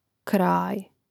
krȃj kraj1